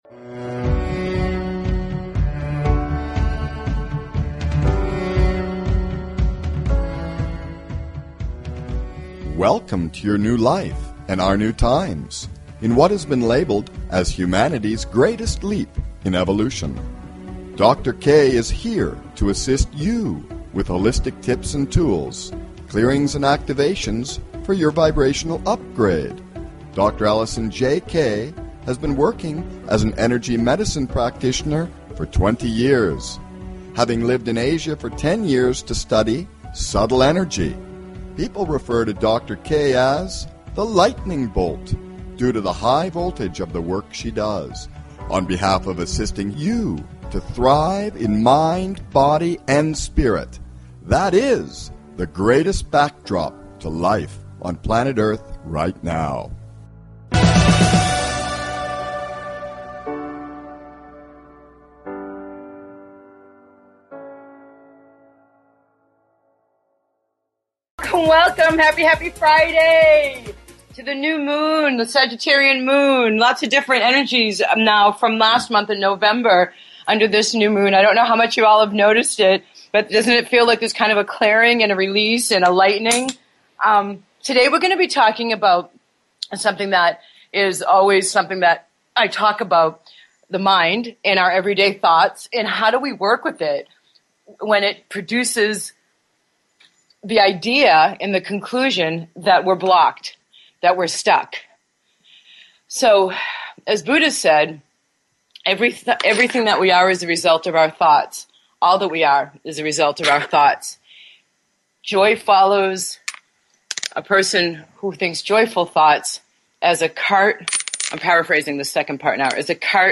Talk Show Episode, Audio Podcast, Vibrational UPgrade and You Are More Than Just Your Mind & Thoughts! Teachings on Detachment & Self-Mastery on , show guests , about Key to Health,Happiness and Wealth,Looking Inside,Why We Are Affraid,Why Are We Affraid, categorized as Health & Lifestyle,Kids & Family,Philosophy,Psychology,Personal Development,Self Help,Spiritual